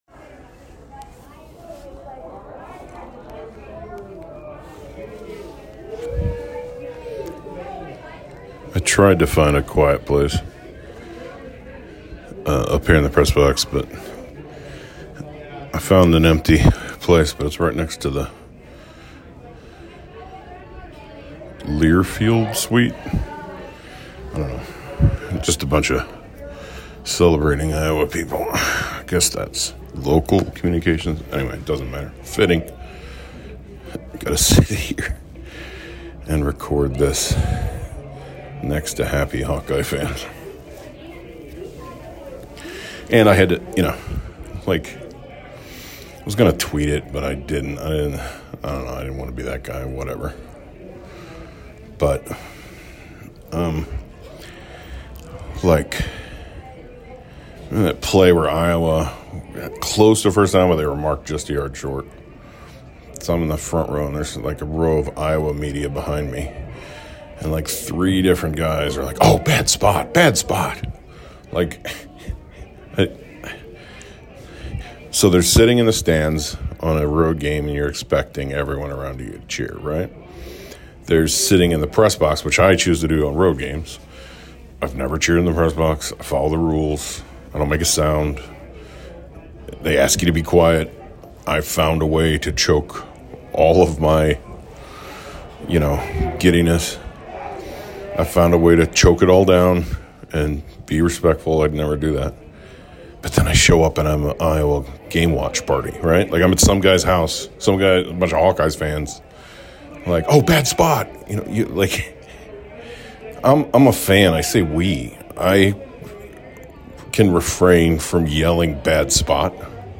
And for me, he happened inside a giddy Iowa pressbox. I went to find an empty room to record this... and I ended up right next to an Iowa celebration in the booth next door.